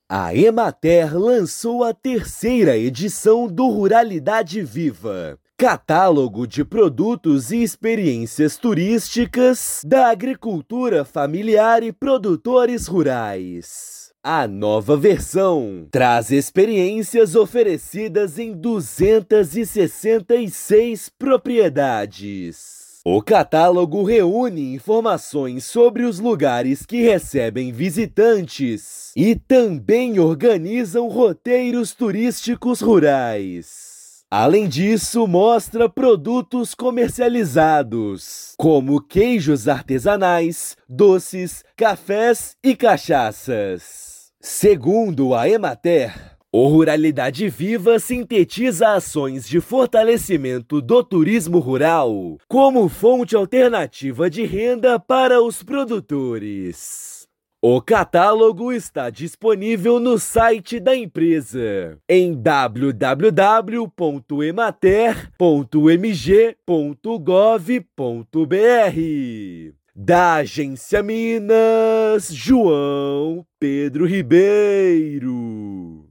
O Ruralidade Viva, da Emater-MG, acaba de ser lançado e tem informações de 266 empreendimentos. Ouça matéria de rádio.